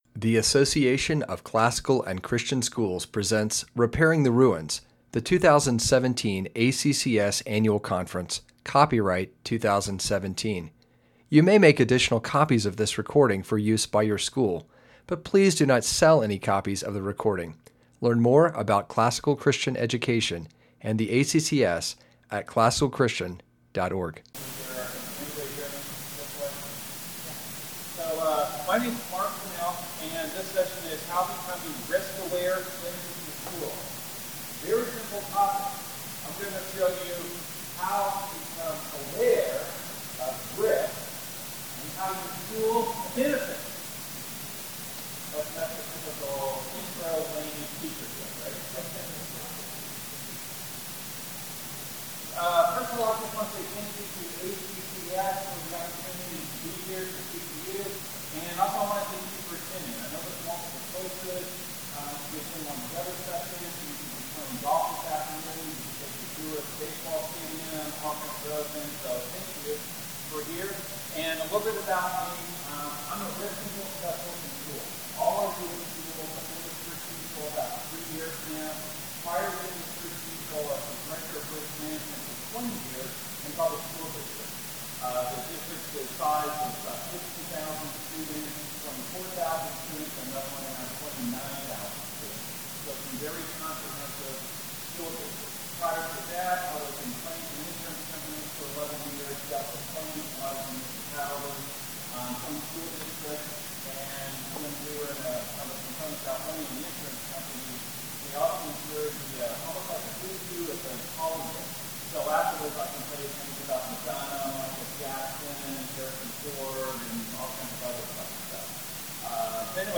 2017 Leaders Day Talk | 0:43:45 | Fundraising & Development
Speaker Additional Materials The Association of Classical & Christian Schools presents Repairing the Ruins, the ACCS annual conference, copyright ACCS.